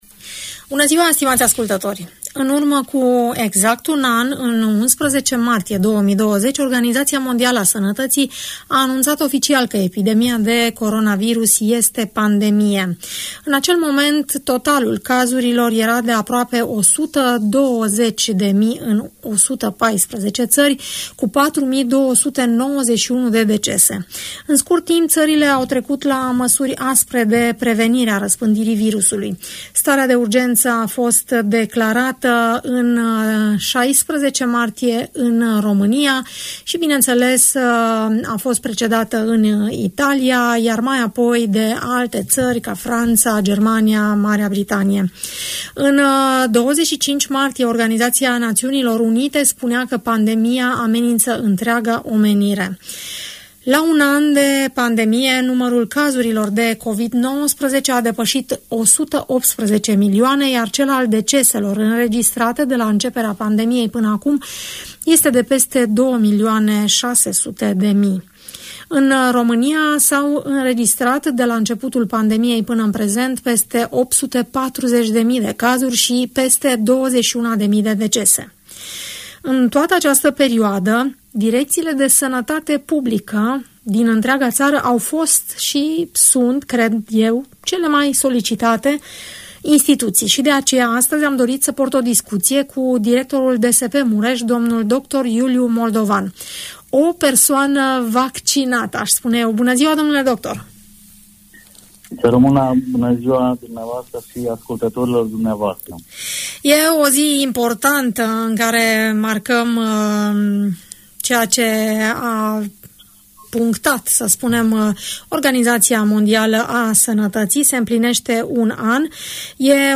Directorul Direcției de Sănătate Publică Mureș, dl dr. Iuliu Moldovan, vorbește la Radio Tg. Mureș despre vaccinarea anticovid, despre testarea persoanelor suspecte de boală și în general despre cele mai importante aspecte ale pandemiei.